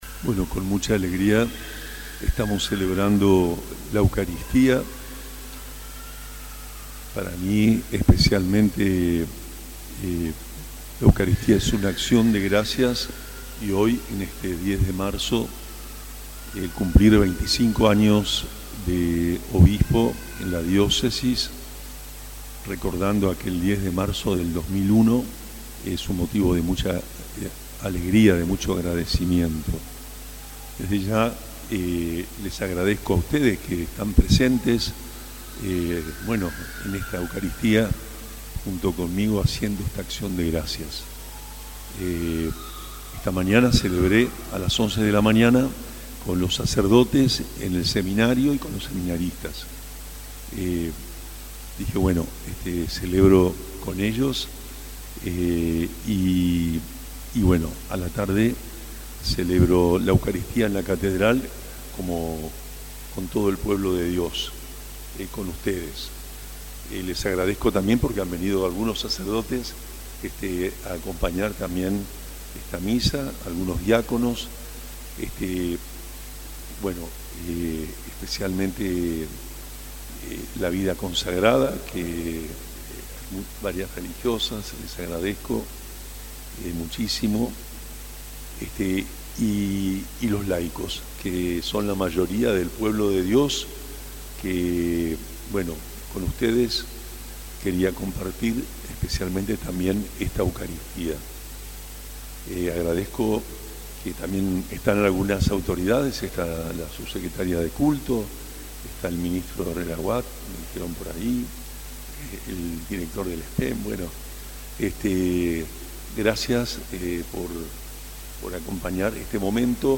El obispo de la diócesis de Posadas, monseñor Juan Rubén Martínez, celebró este martes 10 de marzo 25 años de ministerio episcopal con una misa de acción de gracias en la Catedral San José.
HOMILIA-OBISPO-25-ANOS-misa-misa-online-audio-converter.com_.mp3